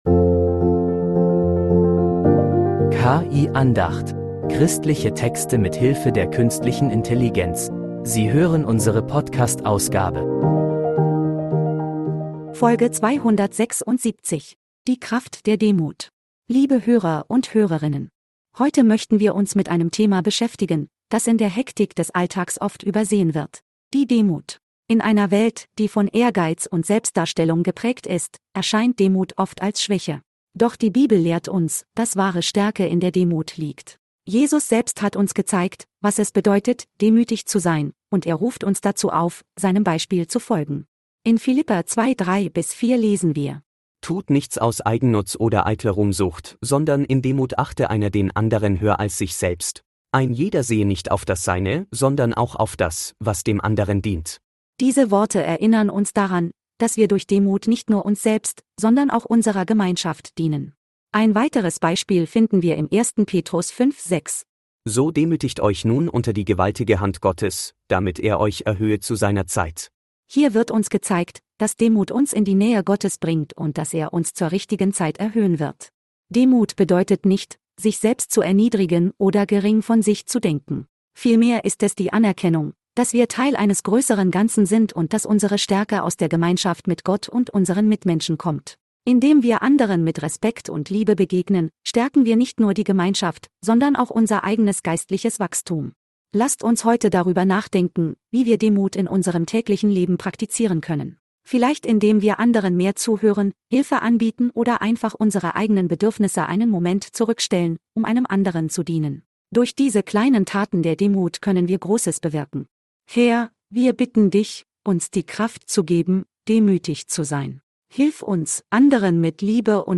Diese Andacht beleuchtet die Kraft der Demut und wie sie uns